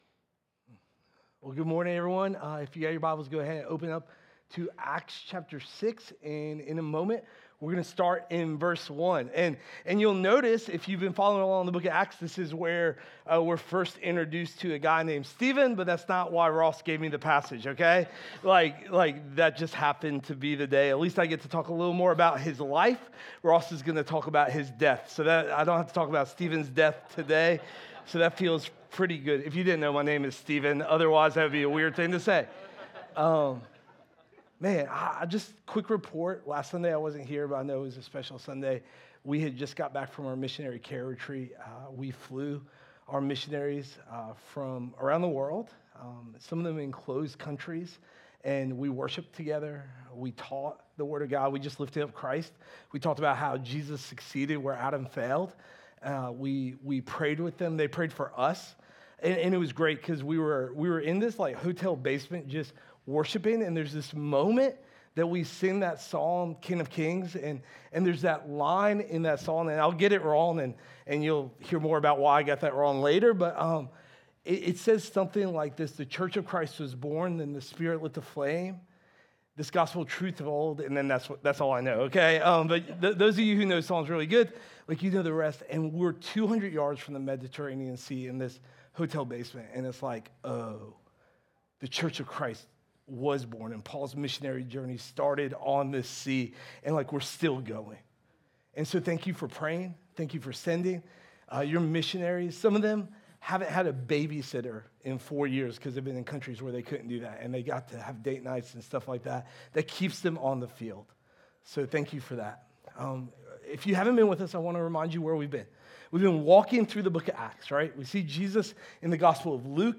Sermon Audio…